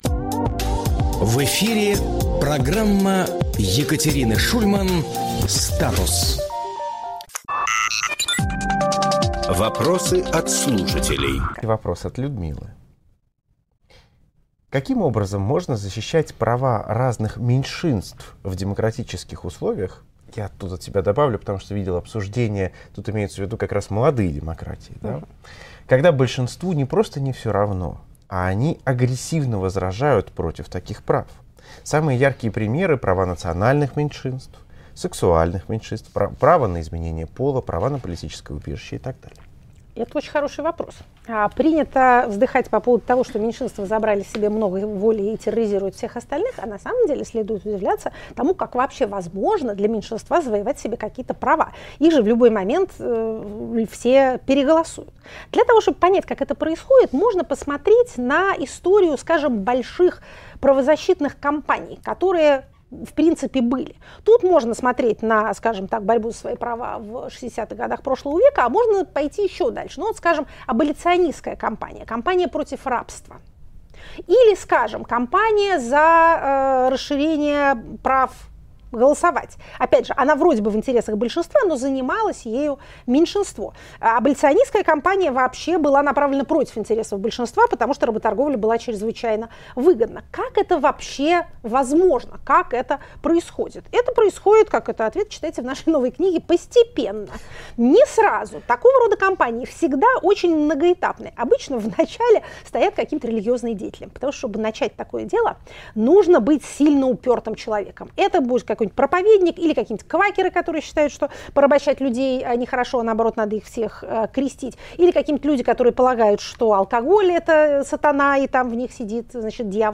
Екатерина Шульманполитолог
Фрагмент эфира от 3 июня.